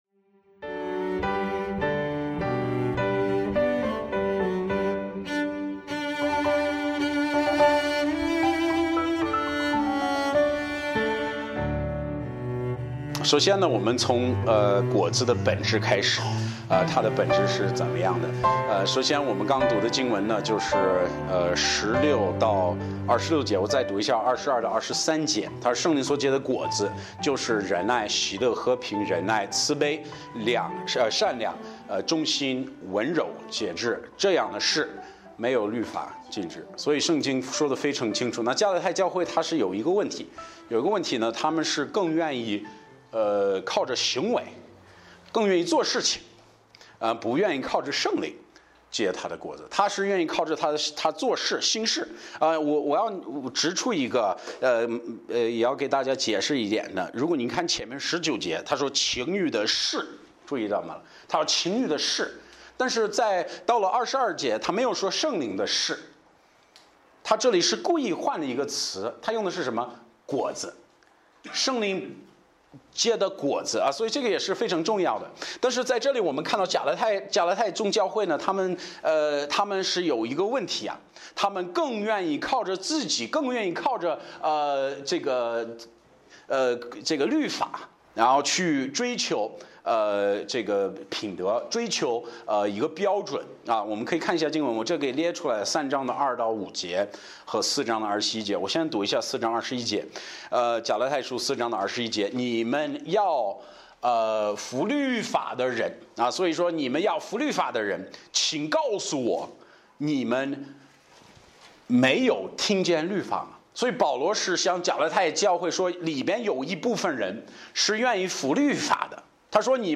Bible Text: 迦拉太书5：16-26 | 讲道者